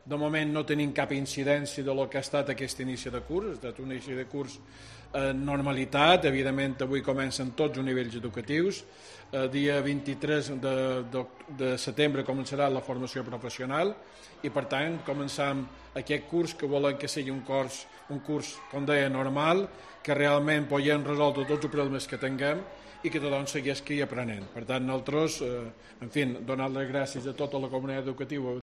Corte de voz del conseller de Educación